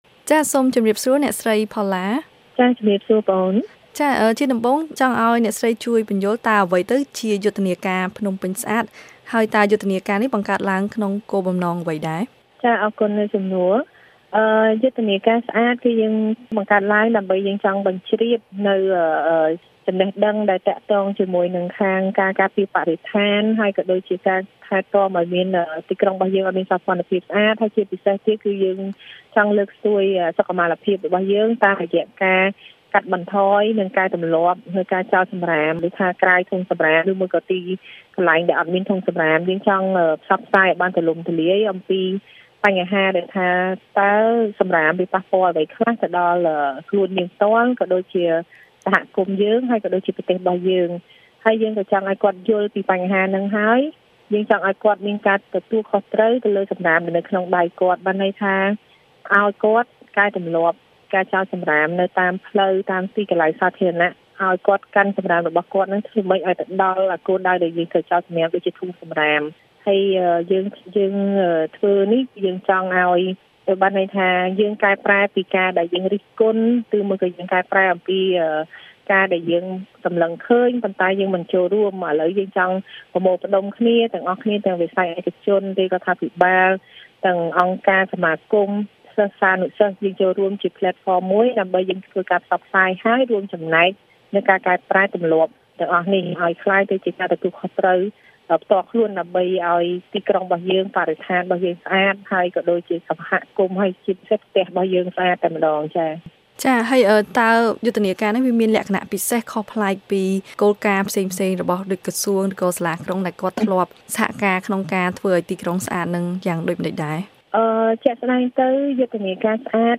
បទសម្ភាសន៍៖ យុទ្ធនាការ«ស្អាត‍»ដើម្បីលើកស្ទួយបរិស្ថានស្អាត